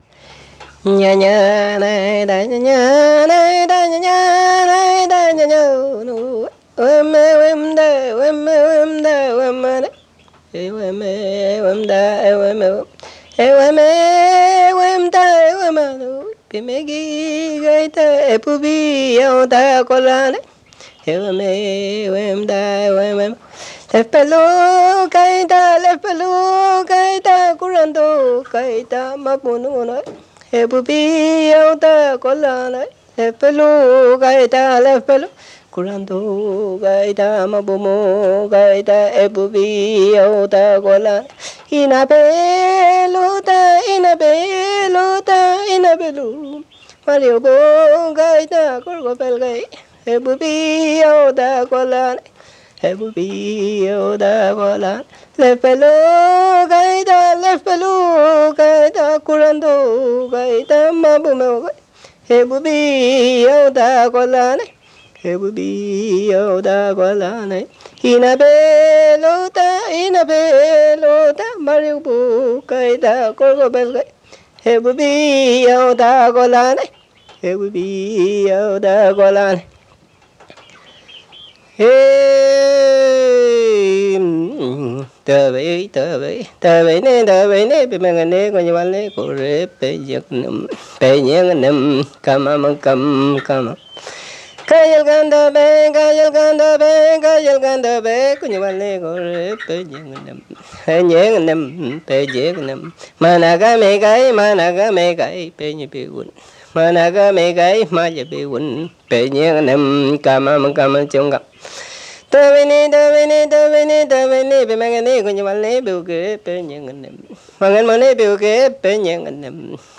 Música mapuche (Comunidad Cerro Loncoche, Metrenco)
Música vocal
Tradición oral